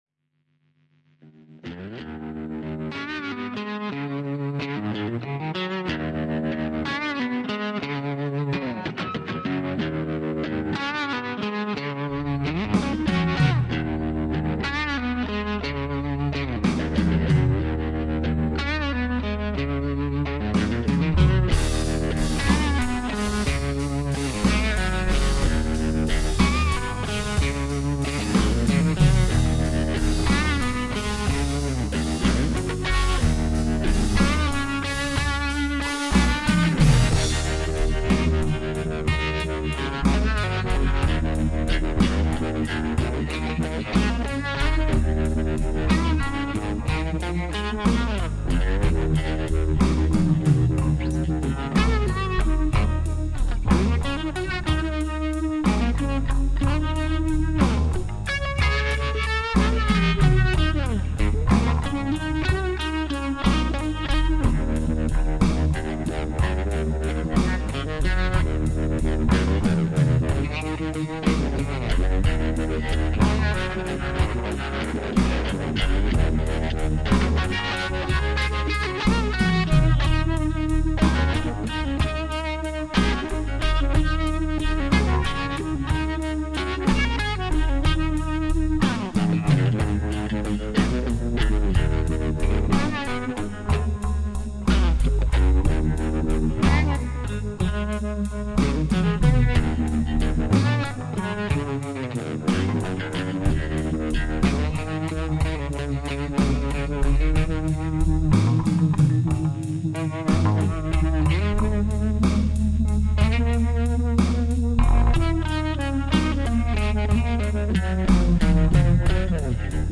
guitars, bass, vocals, programming
organ, vocals